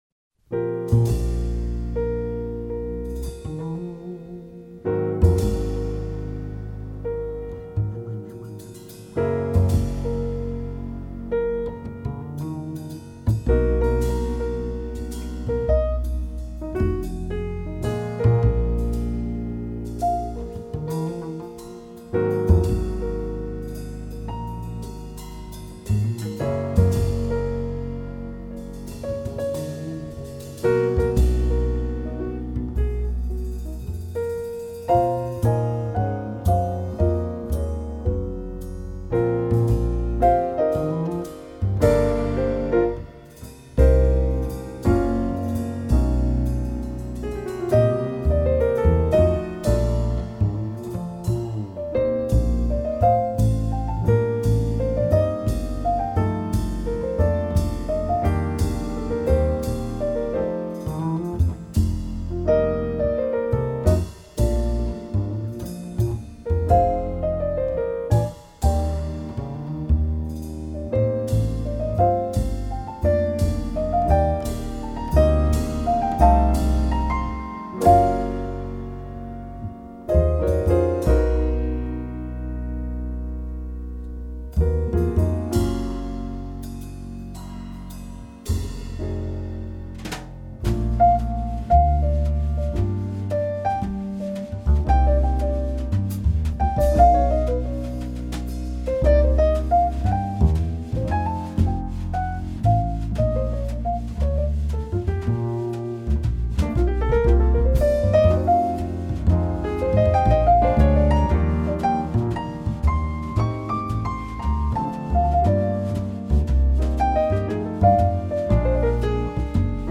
В джазовой обработке